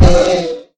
Minecraft Version Minecraft Version latest Latest Release | Latest Snapshot latest / assets / minecraft / sounds / mob / horse / zombie / hit1.ogg Compare With Compare With Latest Release | Latest Snapshot